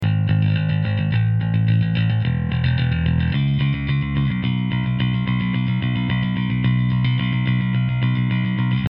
Akorát jsem se dostal ke stopám basy, tak tu dávám pro porovnání kousek, rozdíl je tam (mezi mikrofony a linkou - která je ale PRE-EQ, což to porovnání s linkou trochu kazí).
Mikrofon - D112